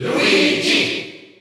Category: Crowd cheers (SSBU) You cannot overwrite this file.